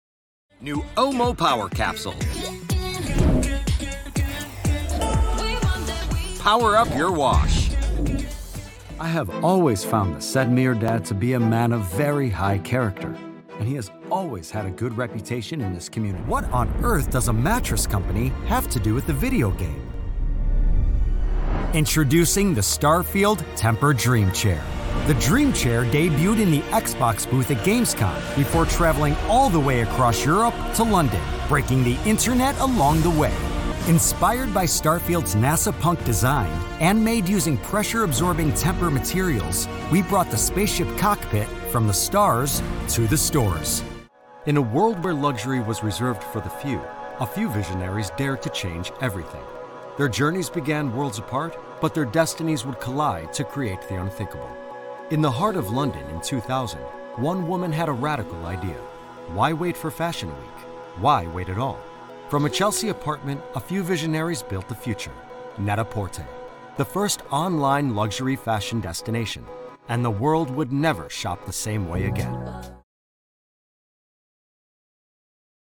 I can sound like the relatable guy next door. Conversational, warm, natural , trustworthy and with empathy .Or I can give a voiceover some sell....
Adult (30-50) | Older Sound (50+) I'm versatile